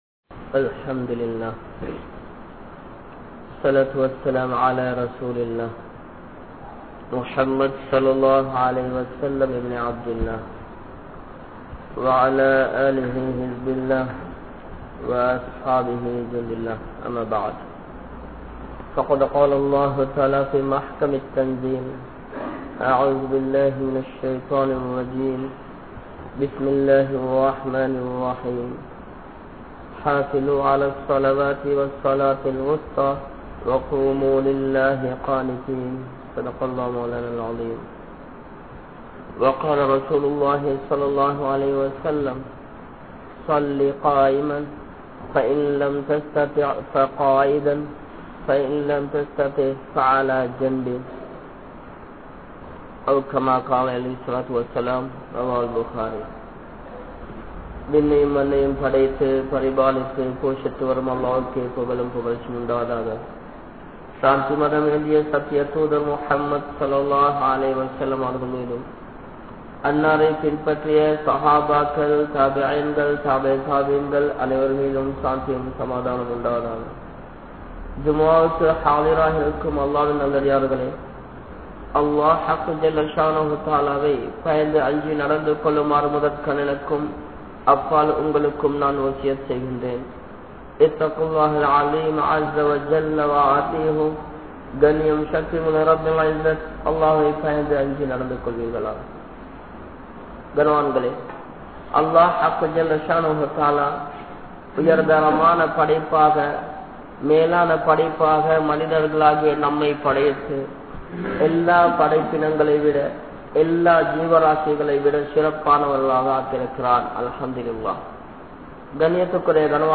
Athihariththu Sellum kathirai Tholuhaiyaalihal (அதிகரித்து செல்லும் கதிரை தொழுகையாளிகள்) | Audio Bayans | All Ceylon Muslim Youth Community | Addalaichenai